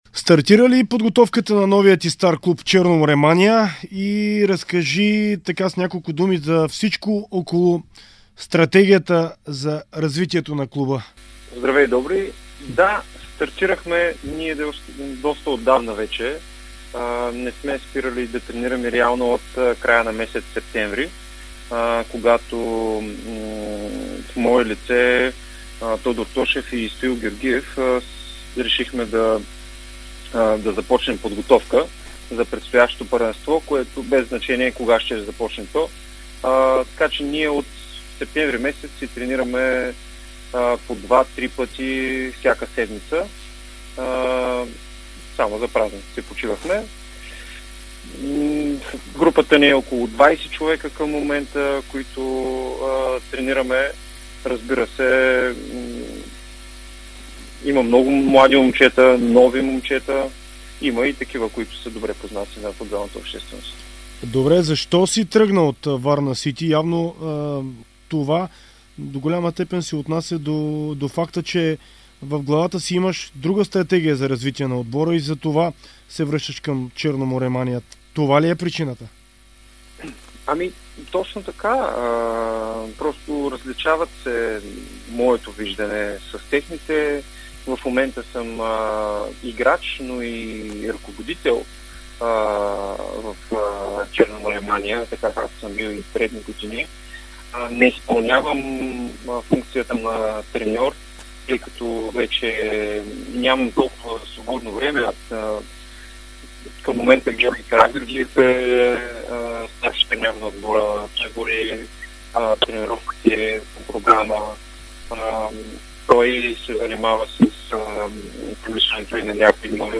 сподели в интервю за Дарик радио и dsport